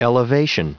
Prononciation du mot elevation en anglais (fichier audio)